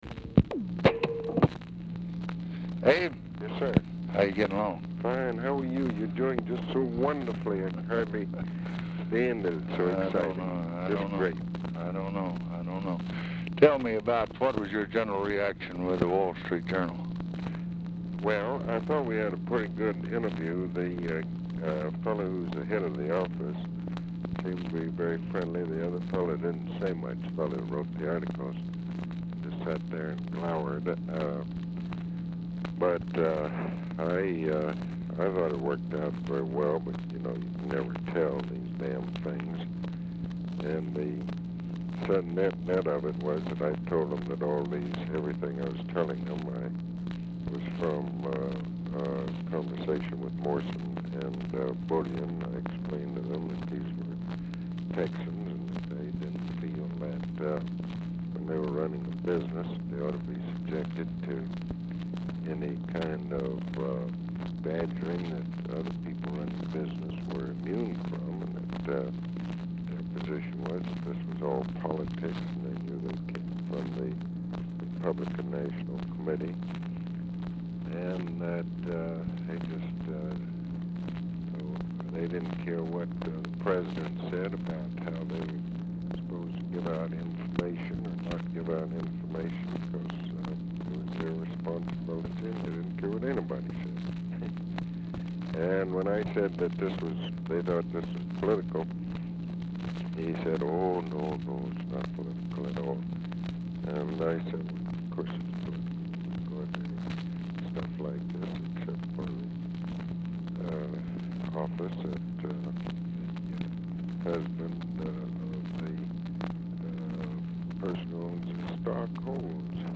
Telephone conversation # 3195, sound recording, LBJ and ABE FORTAS, 4/29/1964, 6:26PM
FORTAS DIFFICULT TO HEAR
Format Dictation belt